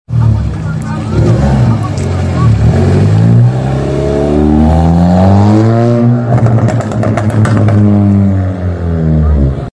Seat ibiza With Loud Exhaust sound effects free download
Seat ibiza With Loud Exhaust And Pops And Bangs!